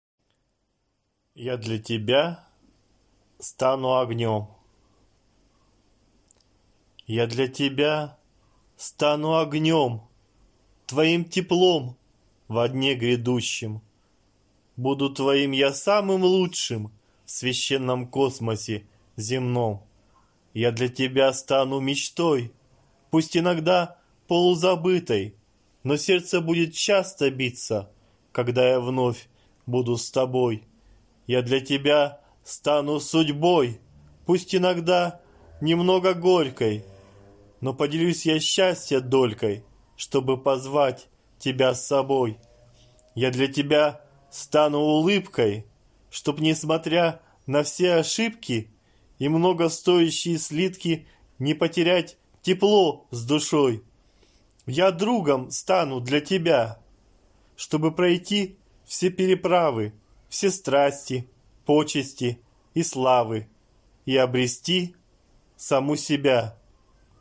Аудиокнига Я для тебя стану огнём. Сборник стихов | Библиотека аудиокниг